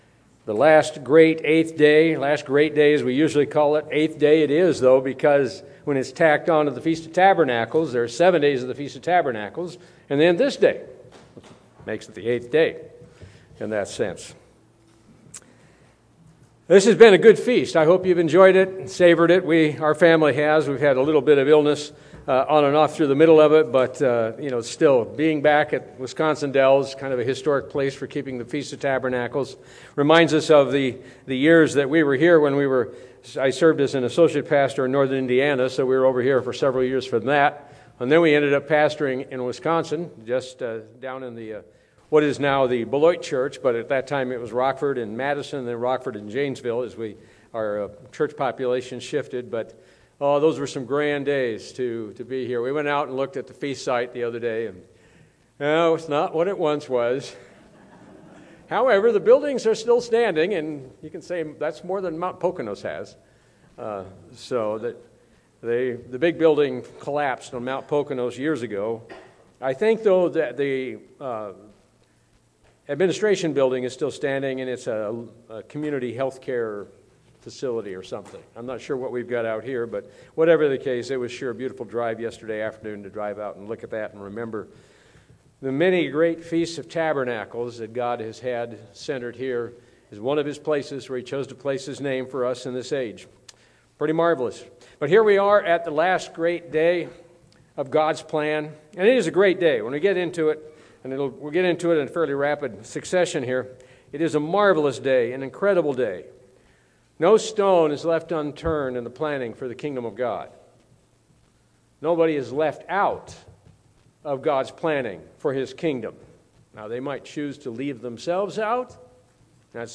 This sermon was given at the Wisconsin Dells, Wisconsin 2019 Feast site.